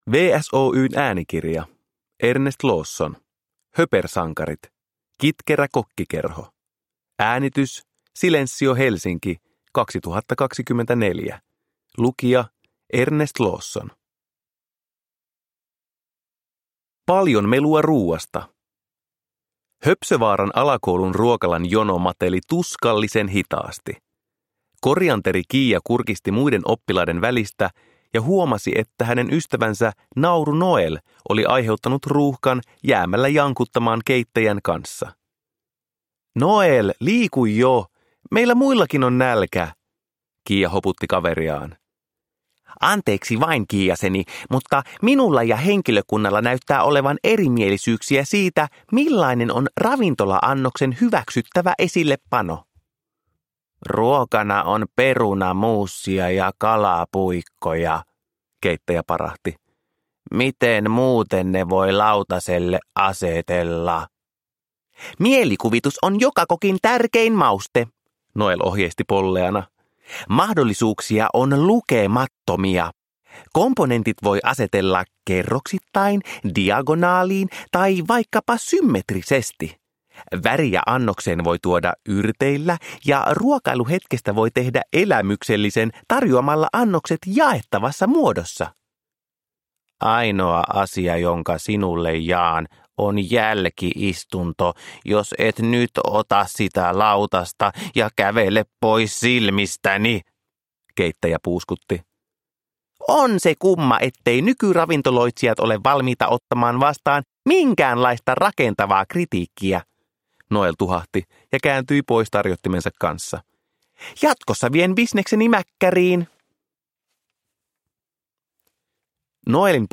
Höpersankarit: Kitkerä kokkikerho – Ljudbok
Uppläsare: Ernest Lawson